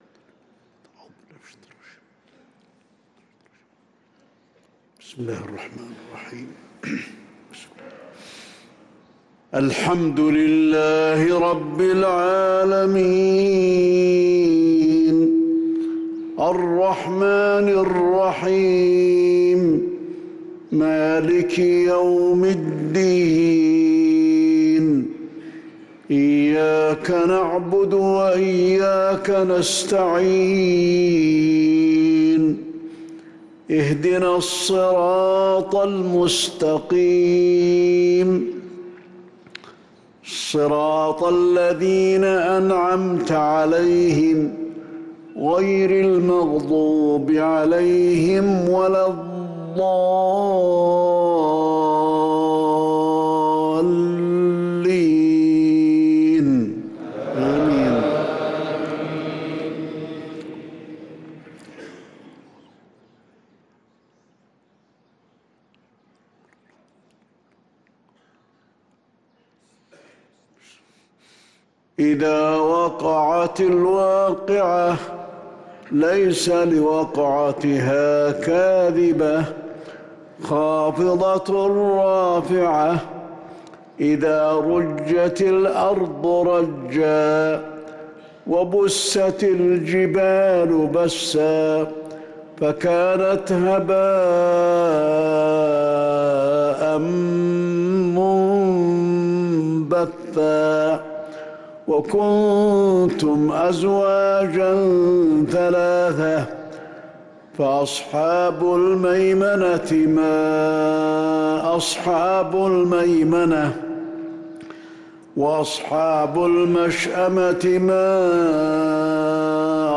صلاة الفجر للقارئ صلاح البدير 12 جمادي الآخر 1445 هـ
تِلَاوَات الْحَرَمَيْن .